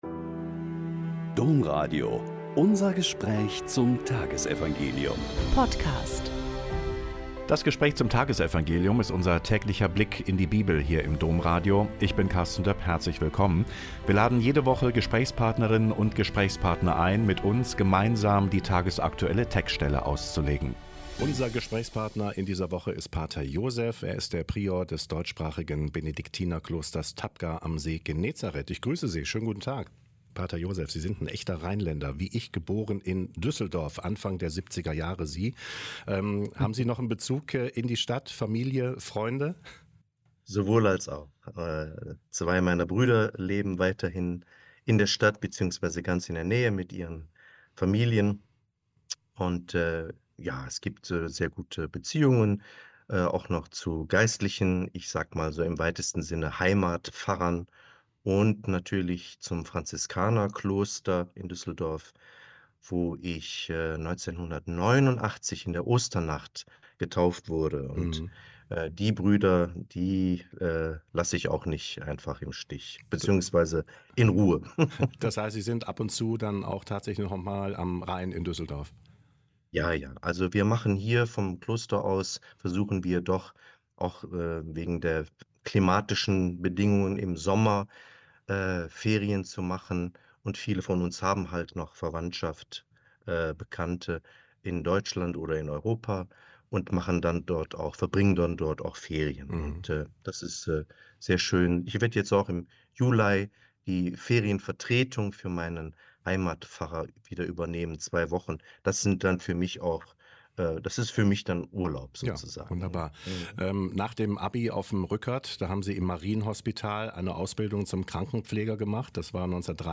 Joh 21,1-14 - Gespräch